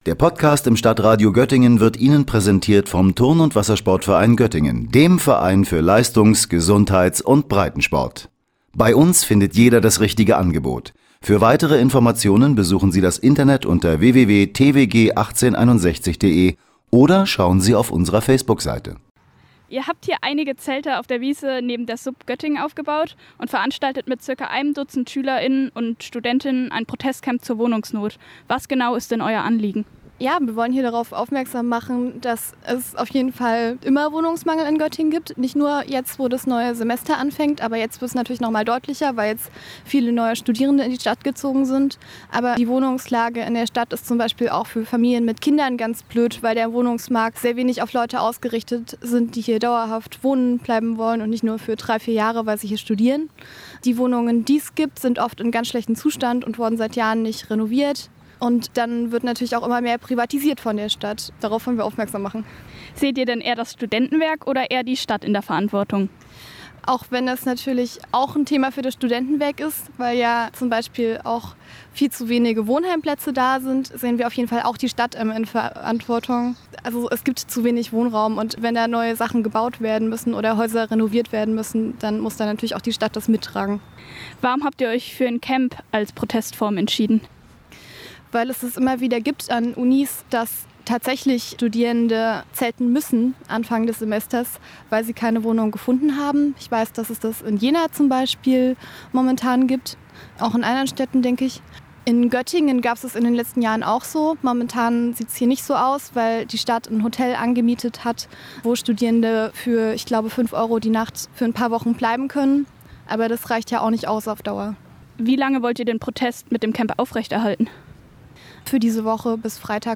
Die Interviewpartnerin wollte lieber anonym bleiben.